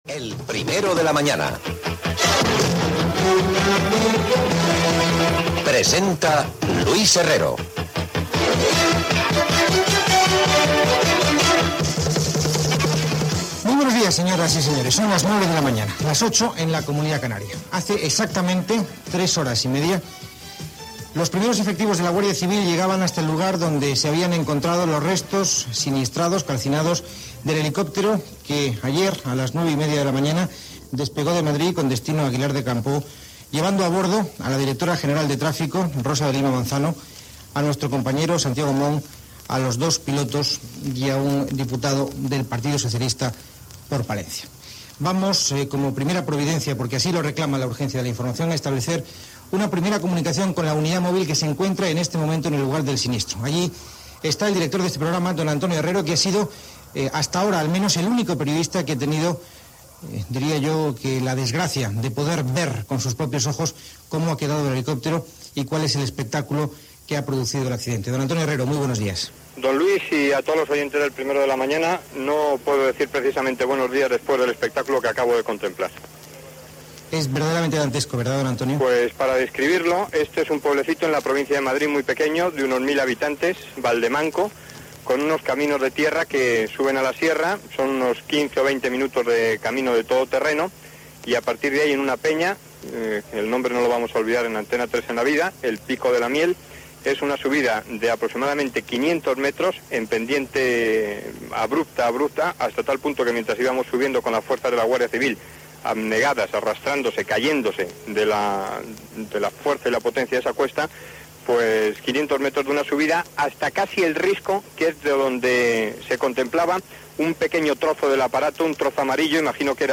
Careta, hora, informació
Connexió amb la unitat mòbil d'Antena 3 Gènere radiofònic Info-entreteniment